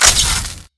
damaged.ogg